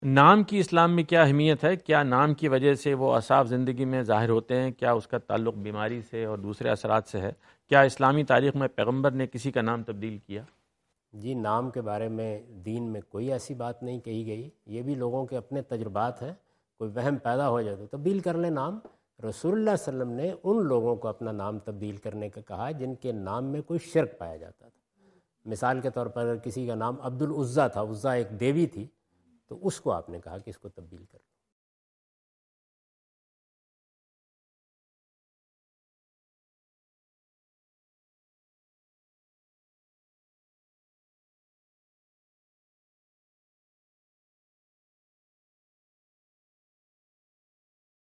جاوید احمد غامدی اپنے دورہ امریکہ2017 کے دوران فلیڈیلفیا میں "ناموں کے شخصیت پر اثرات" سے متعلق ایک سوال کا جواب دے رہے ہیں۔